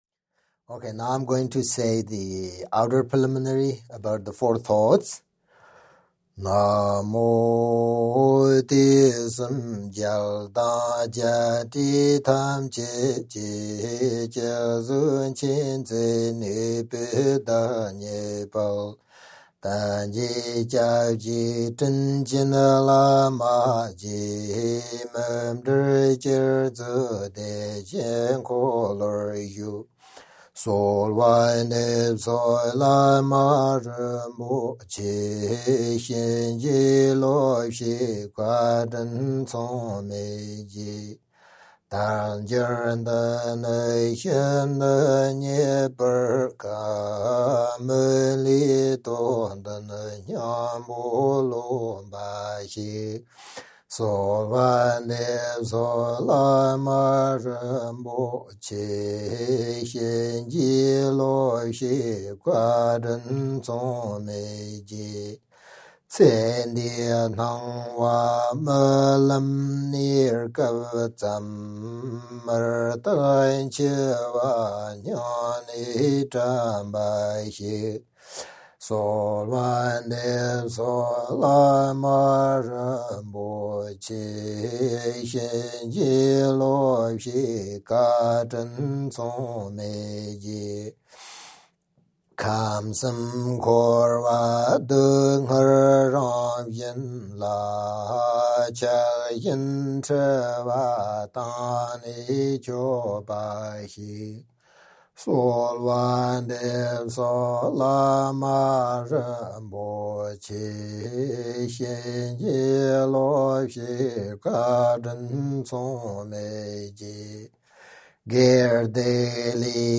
Ngöndro Mantras
The-Chariot-of-Liberation-Ngöndro-in-Tibetan.mp3